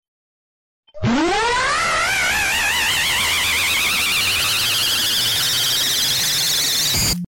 The Doombringer‘s screaming and death
The Doombringer‘s screaming and death sequence from Roblox Grace.